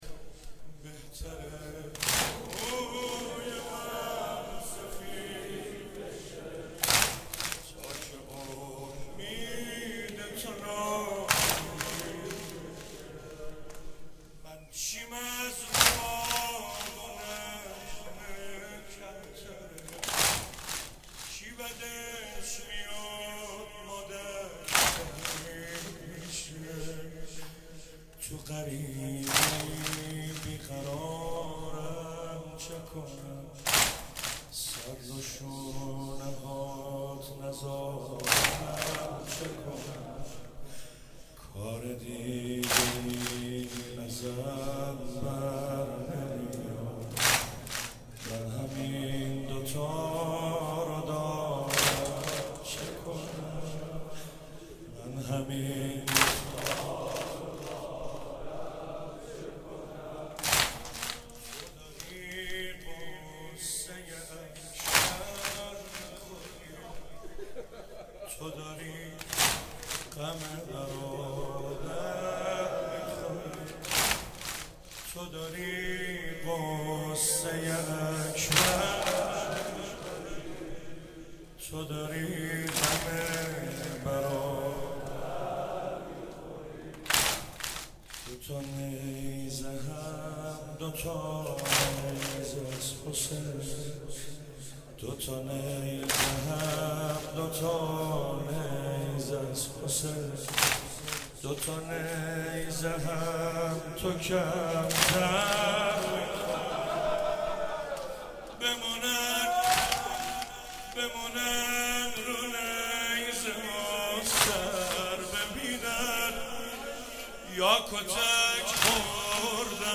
شب پنجم محرم 96 - واحد - بهتر روی من سفیذ بشه